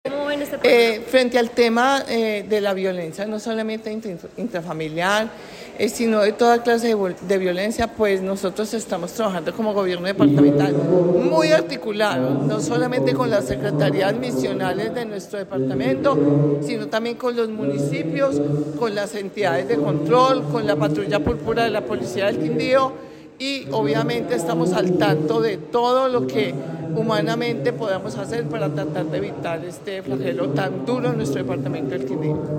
Secretaria de Familia del Quindío